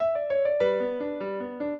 piano
minuet12-11.wav